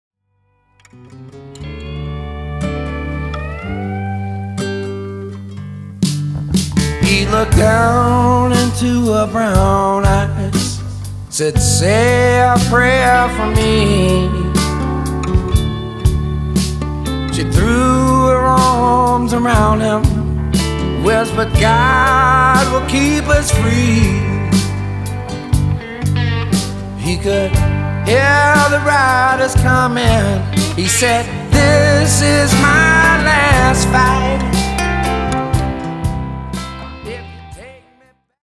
Vocal & Lap Steel Guitar
Backing Vocal & Classical Guitar
Recorded at Tony’s Treasures Studio, Cadiz, Ohio.